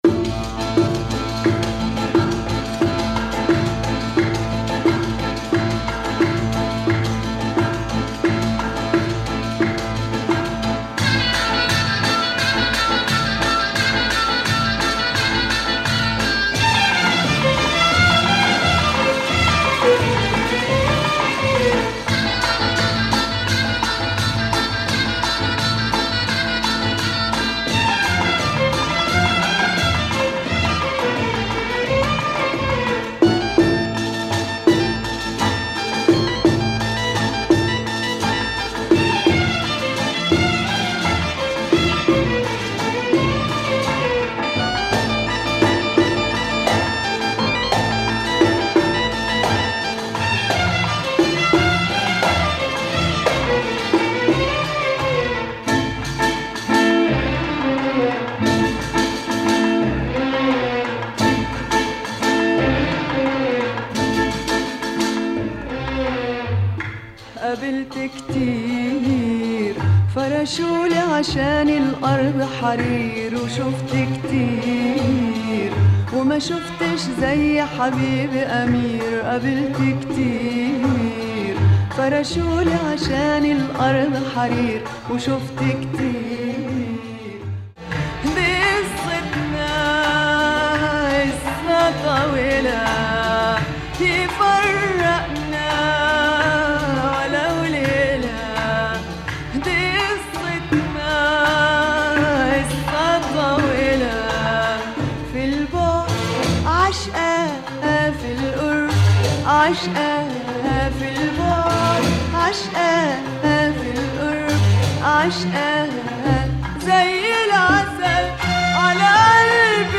Lebanese groove !